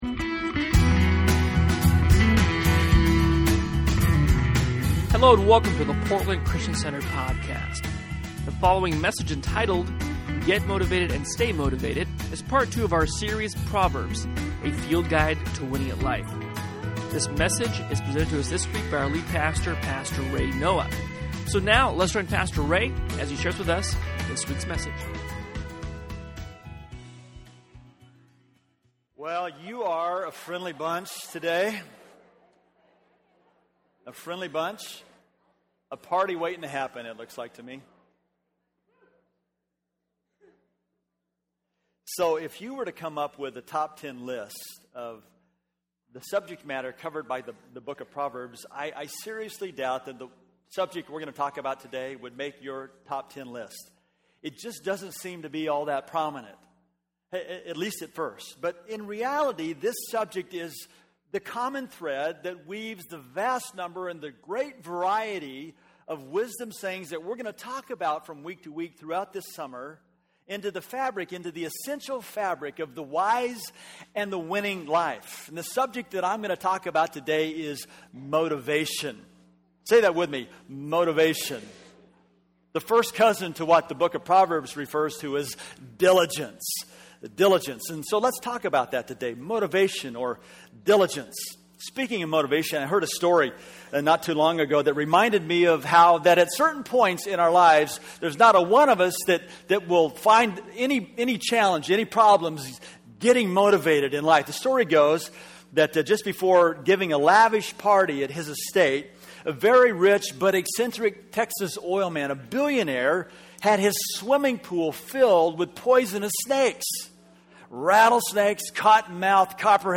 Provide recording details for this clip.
Sunday Messages from Portland Christian Center